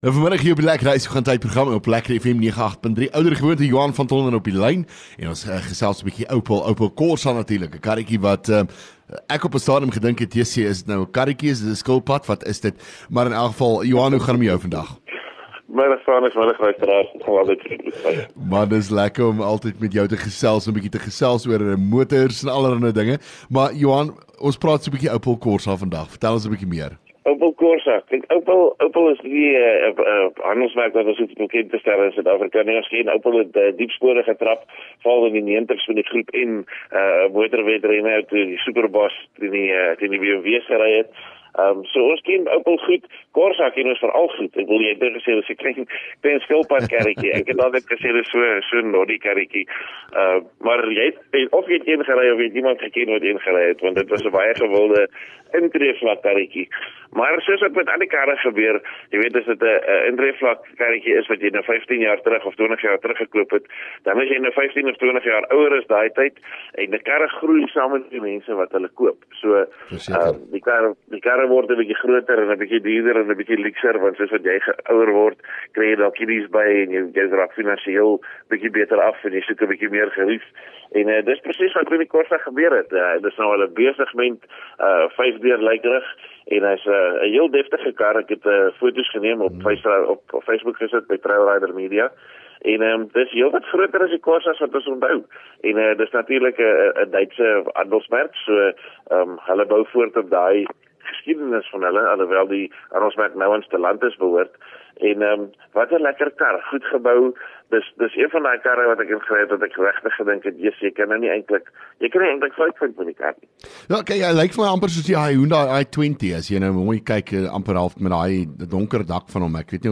LEKKER FM | Onderhoude 15 Nov LEKKER Kar Praat